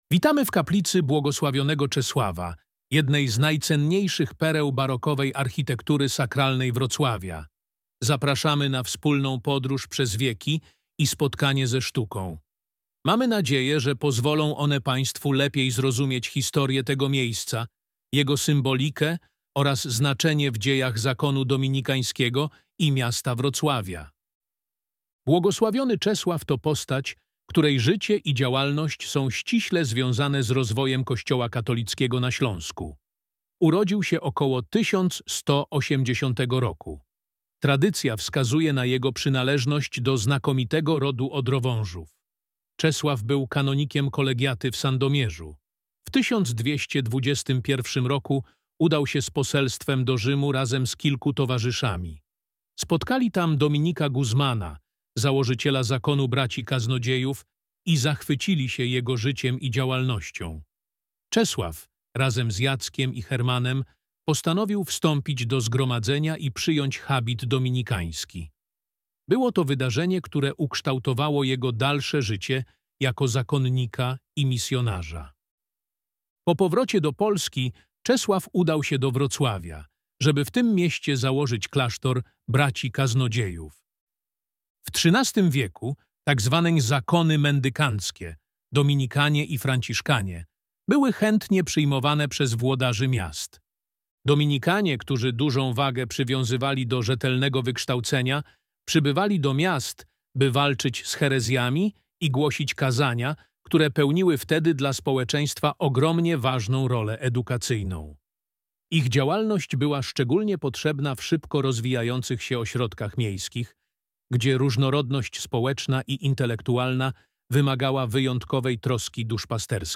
Kaplica bł. Czesława – audioprzewodnik